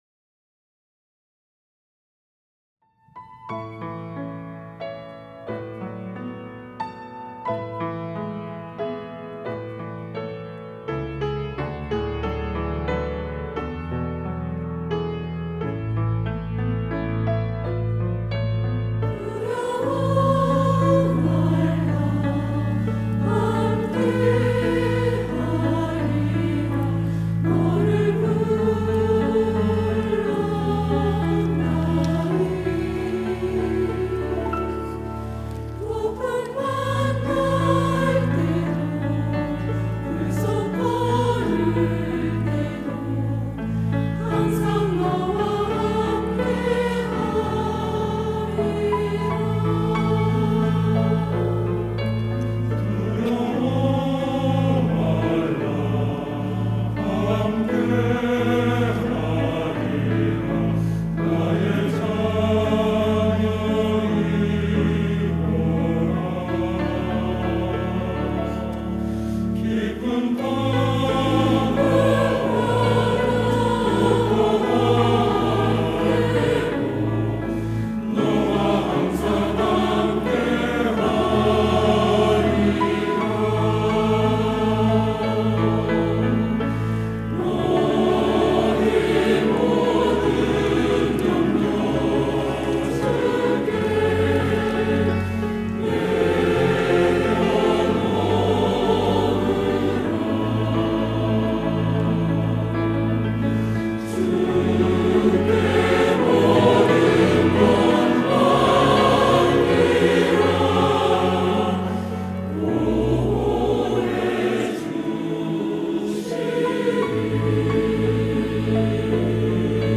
시온(주일1부) - 두려워말라 너는 내 것이라
찬양대